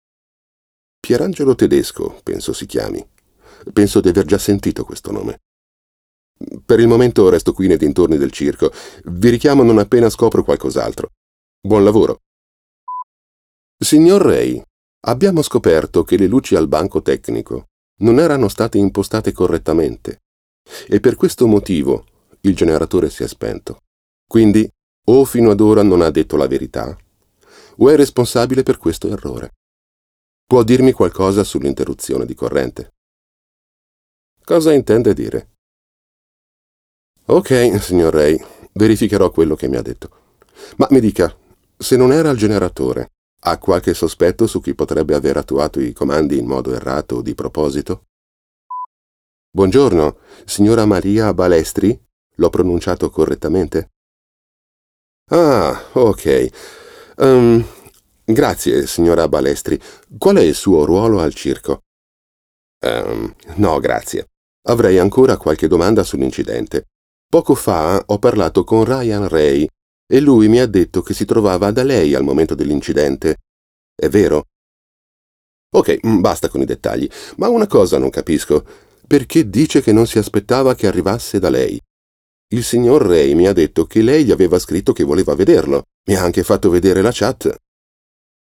Male
Adult (30-50)
Video Games
Detective